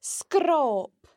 The broad sgr can be heard in sgròb (a scratch):